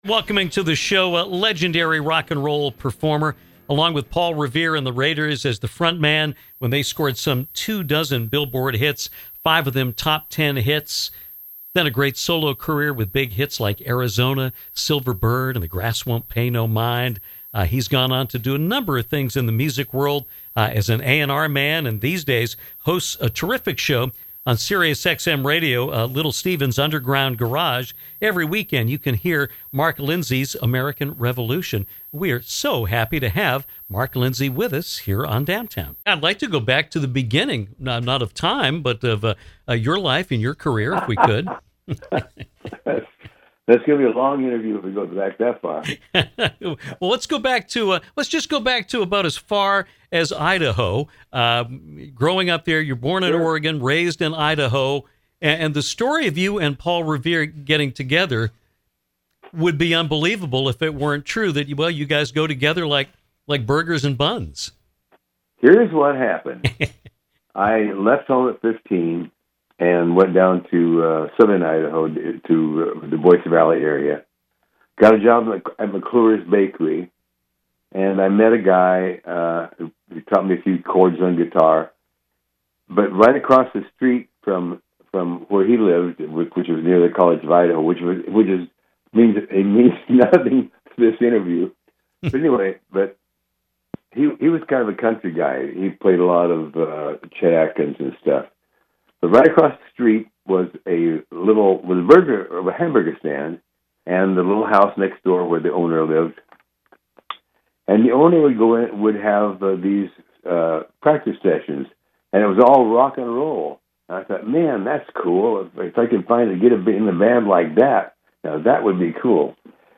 Great interview.